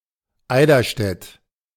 Eiderstedt (German: Eiderstedt, pronounced [ˈaɪdɐʃtɛt]